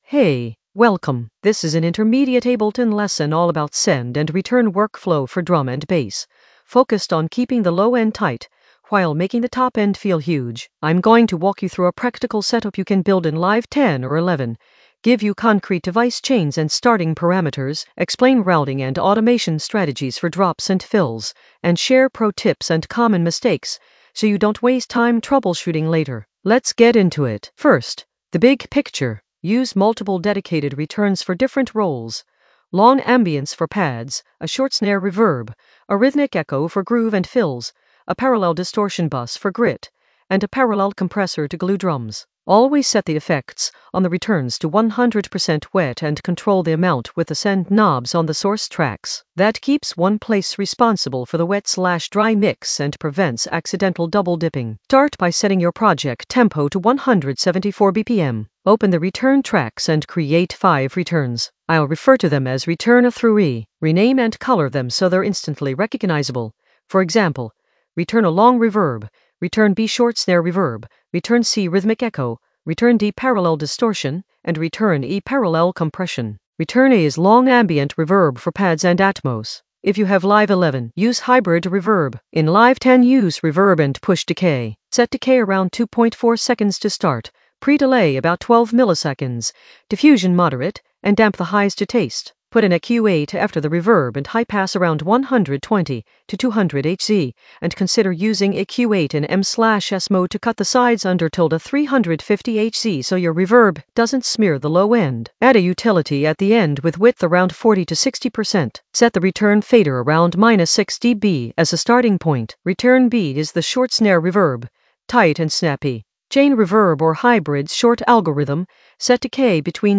An AI-generated intermediate Ableton lesson focused on Send return workflow in the Mixing area of drum and bass production.
Narrated lesson audio
The voice track includes the tutorial plus extra teacher commentary.
send-return-workflow-intermediate-mixing.mp3